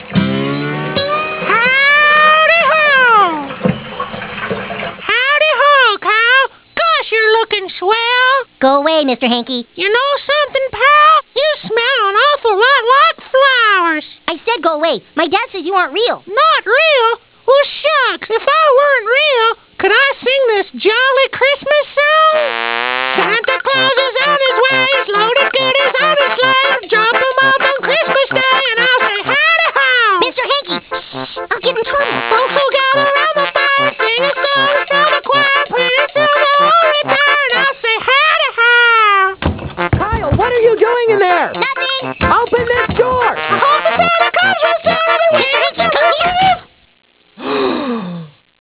( hij zingt een kerst liedje voor Kyle )